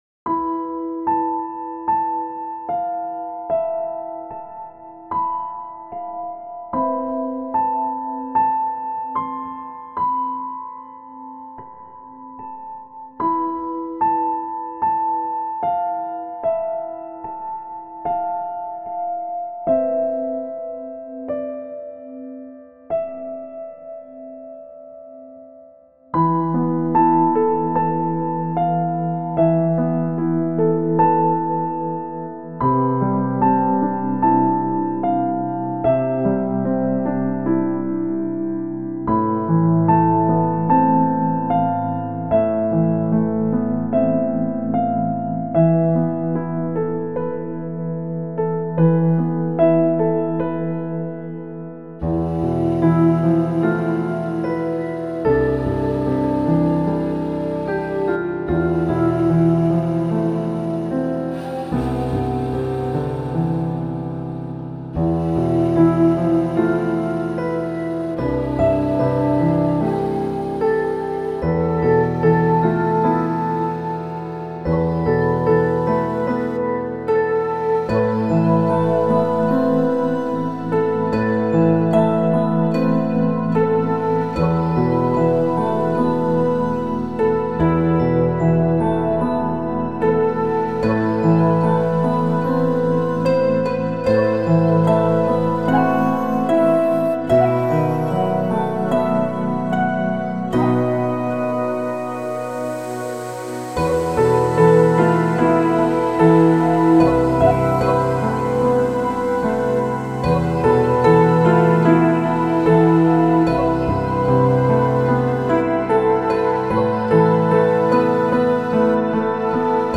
テンポ：♩=74.2
主な使用楽器：ピアノ、尺八、琴、シンセパッド etc